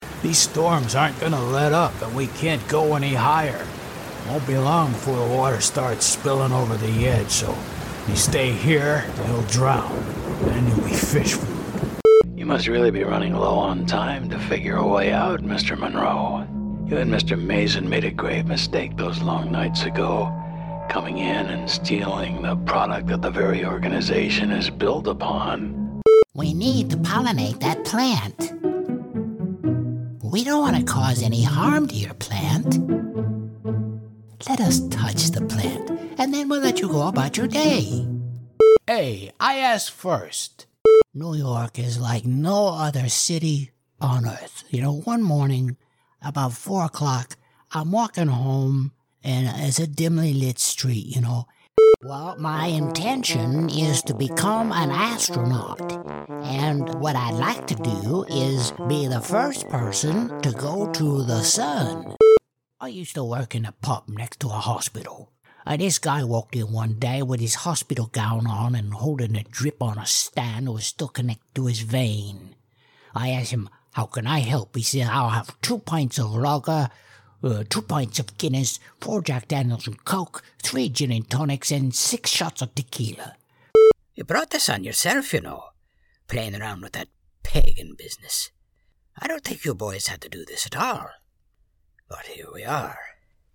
Drama Reel
Irish, American South
Middle Aged
Senior